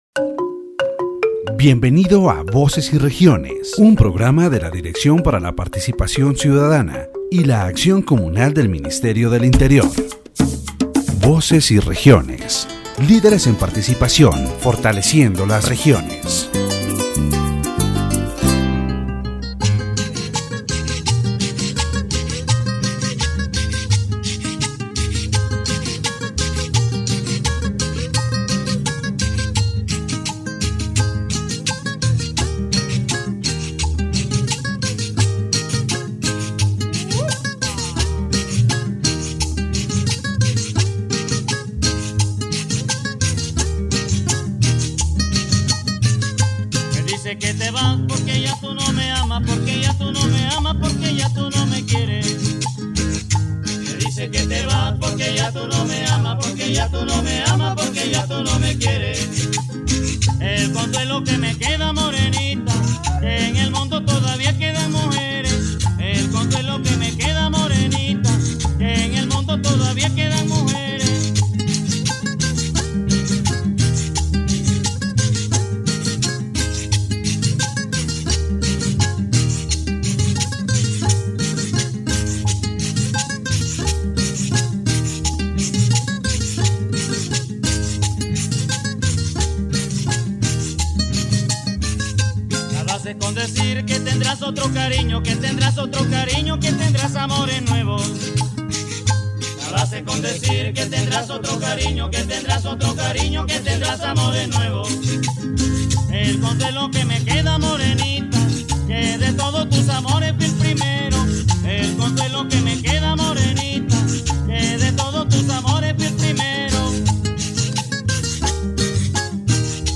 The radio program "Voces y Regiones," broadcast by UEstéreo 90.4 in collaboration with the Ministry of the Interior, focuses on the political and social situation in La Guajira, Colombia.